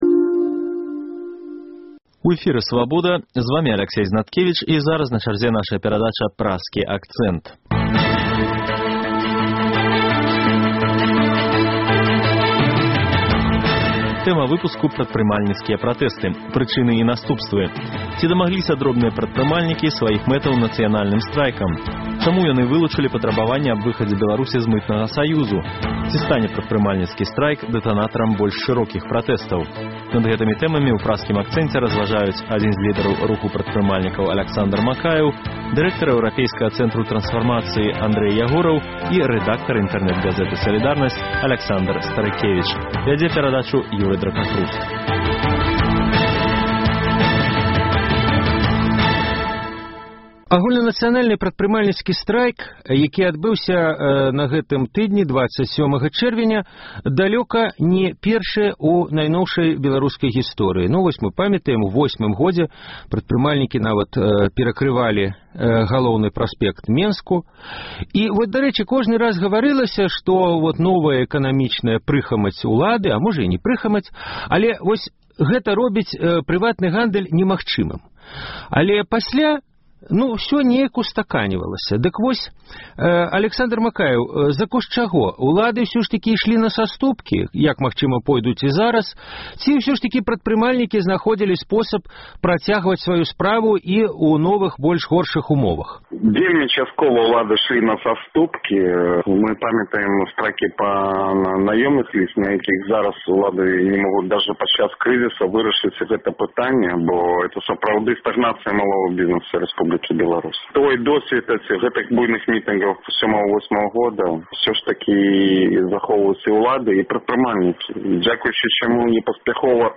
Праскі акцэнт: штотыднёвы круглы стол экспэртаў і аналітыкаў на актуальную тэму.